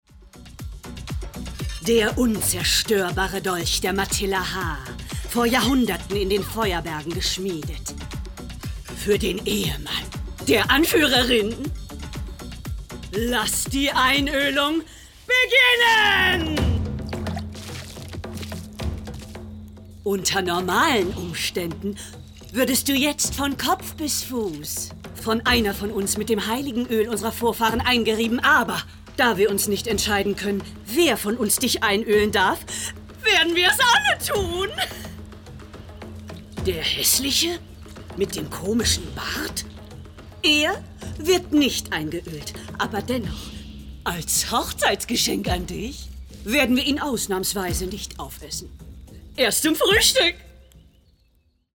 Animation_beschwörerisch, überdreht